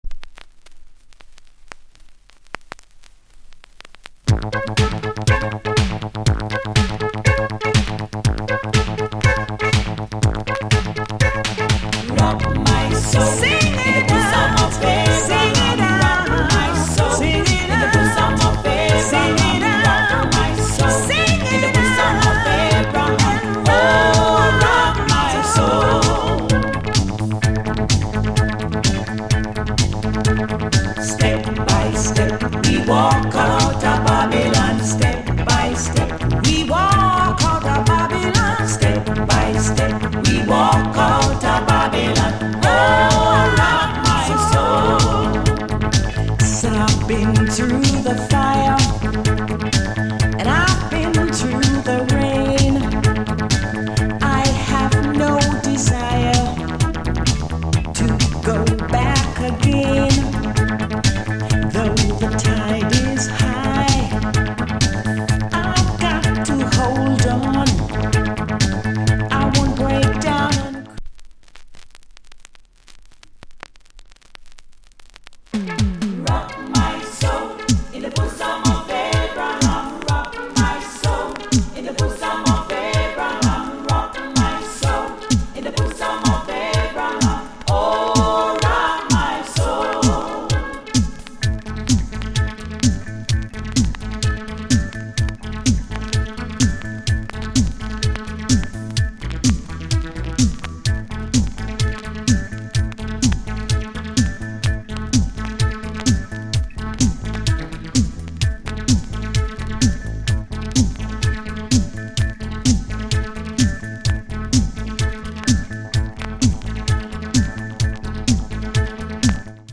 Disco Cut